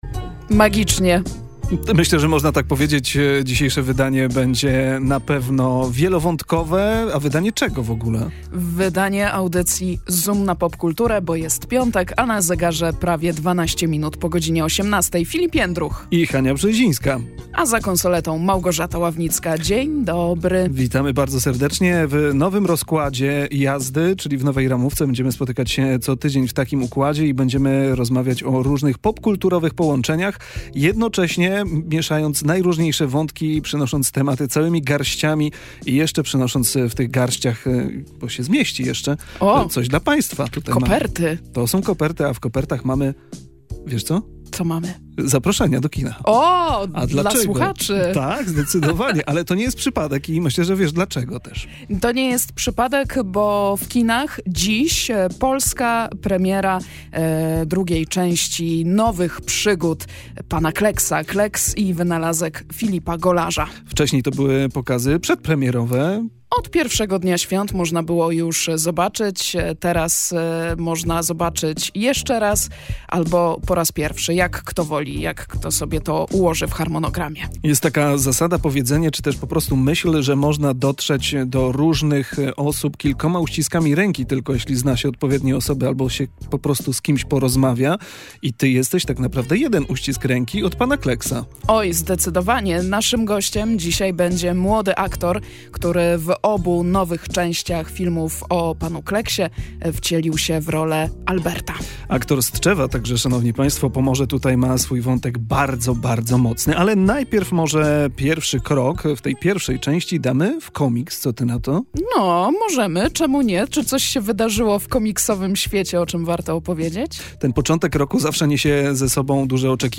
Audycję zakończyliśmy konkursem dla słuchaczy, pytając o ich ulubione bajki i pomysły na nowe historie.